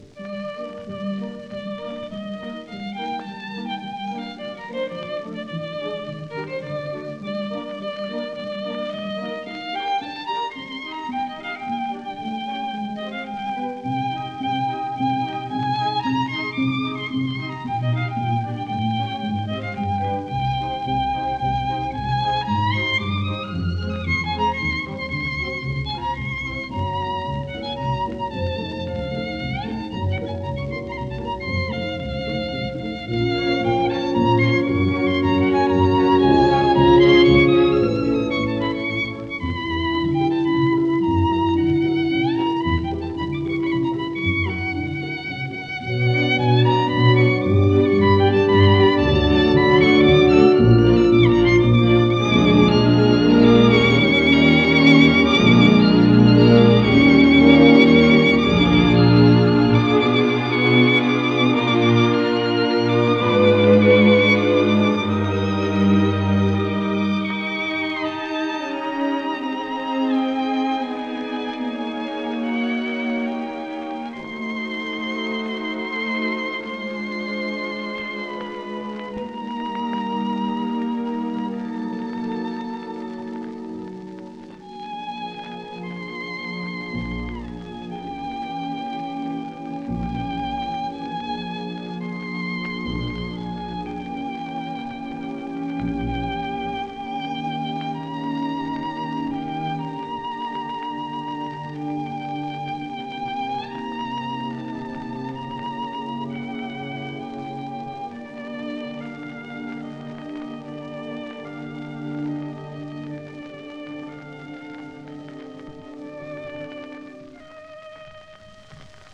This is the classic recording